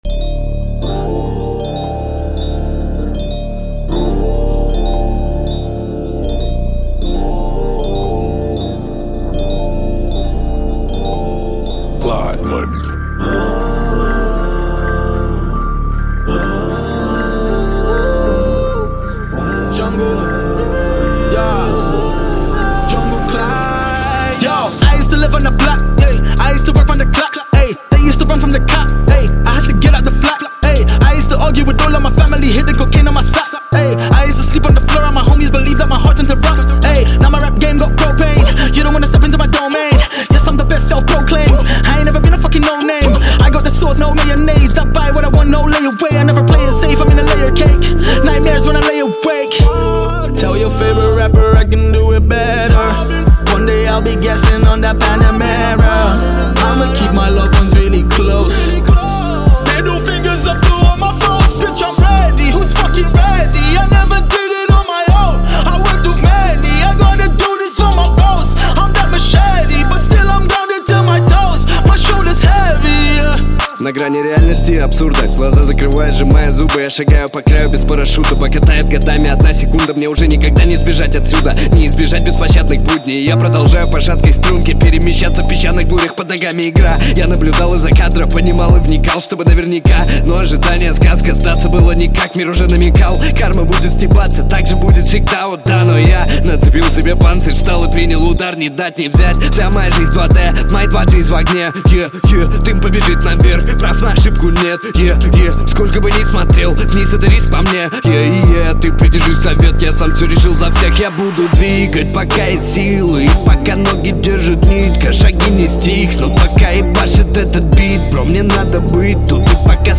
Жанр: Жанры / Хип-хоп